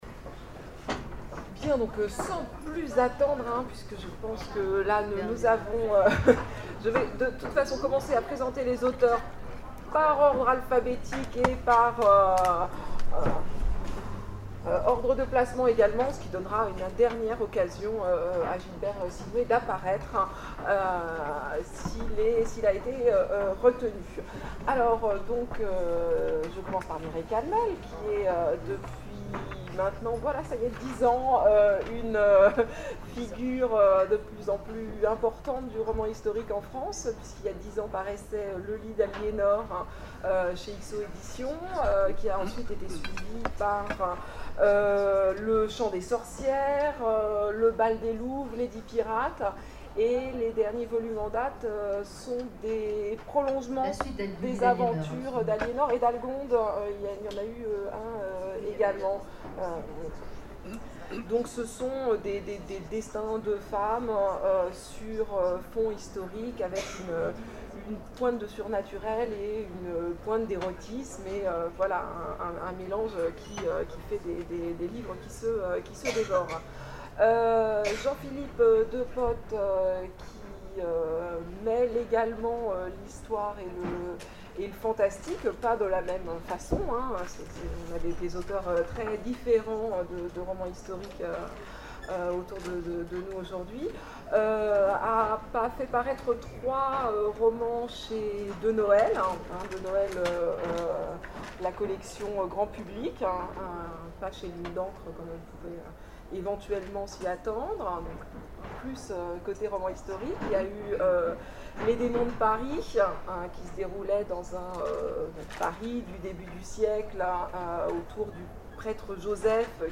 Imaginales 2012 : Conférence Le roman historique